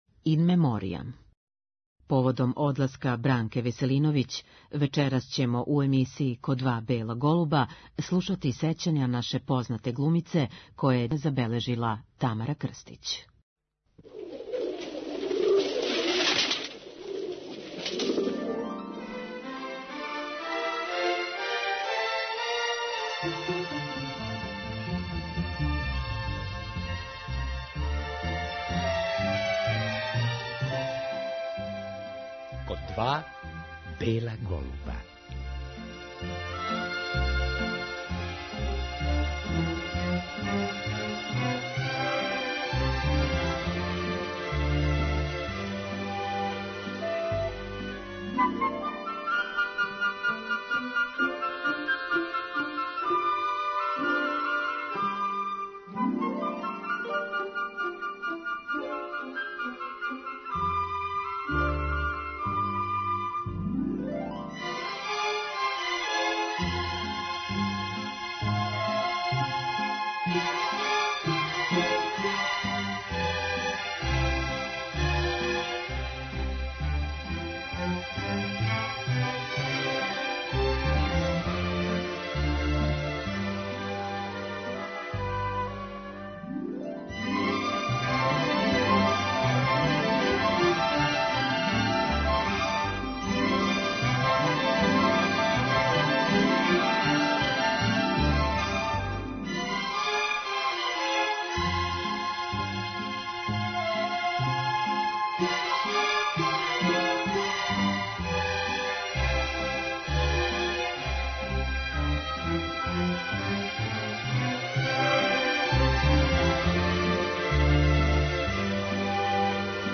Поводом одласка Бранке Веселиновић у вечерашњој емисији слушаћемо сећања наше познате глумице забележена 2017. године, поводом њеног 99. рођендана.